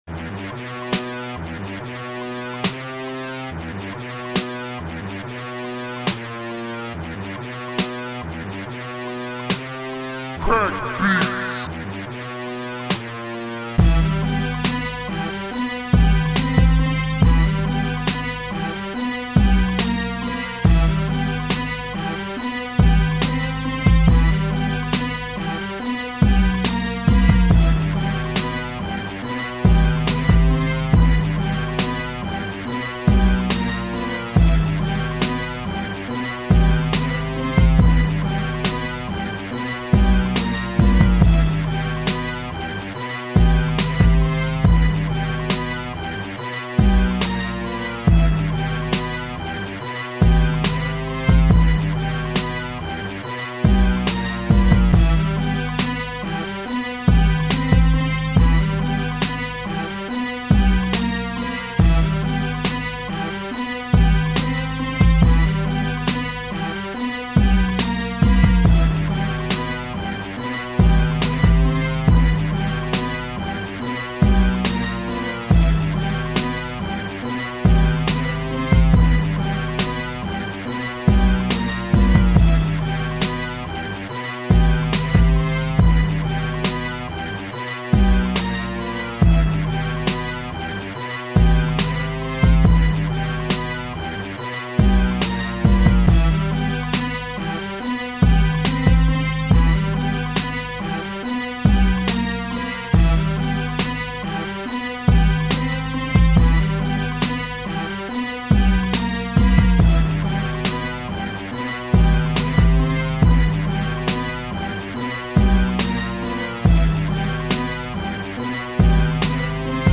Cool FX
Claps
Kicks
Snares
Percs
Hi hats